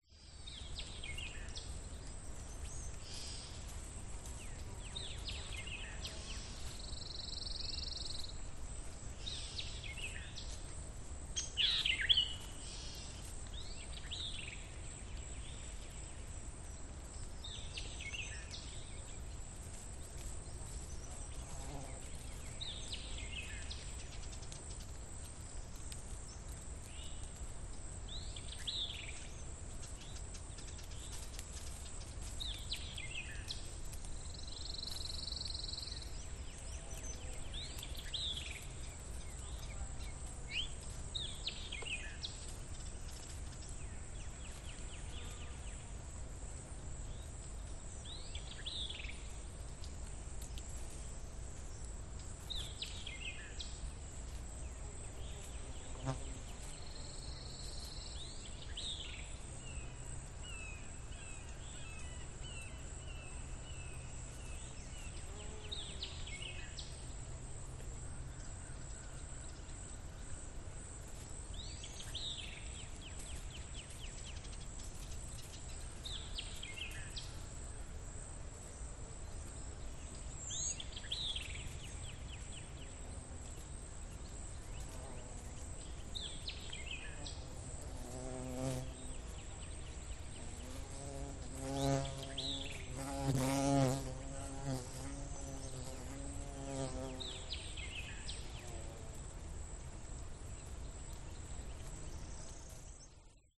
Звуки природы
На дачном участке